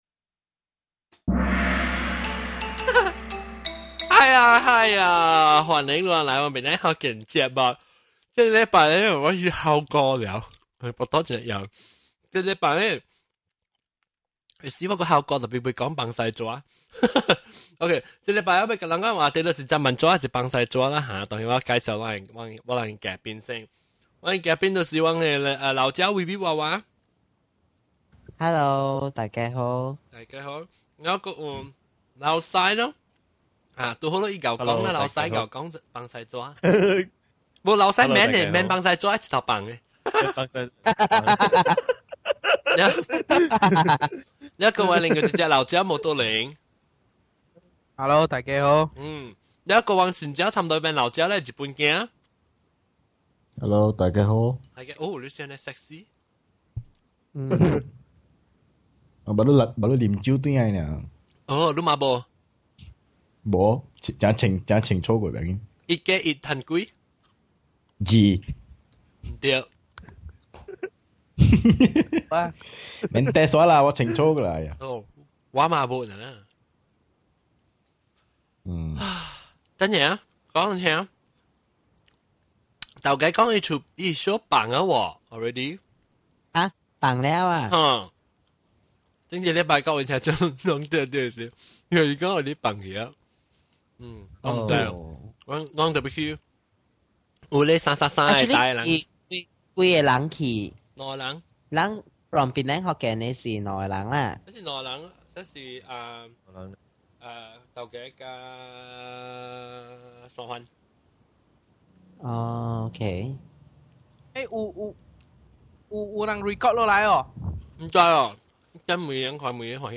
Thanks for letting me know about the missing chunk in the middle.
We are talking about toilet paper! Listen to our guests sharing their tips on wiping and their favorite brands of toilet paper.